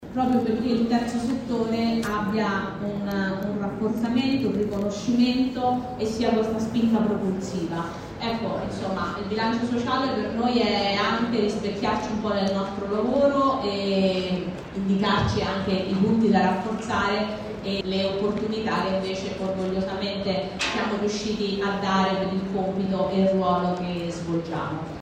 Con i Bambini sostiene le aree colpite dall’alluvione dello scorso anno in Emilia-Romagna, Toscana e Marche. Il servizio